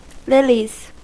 Ääntäminen
Ääntäminen US Tuntematon aksentti: IPA : /ˈlɪliz/ Haettu sana löytyi näillä lähdekielillä: englanti Lilies on sanan lily monikko.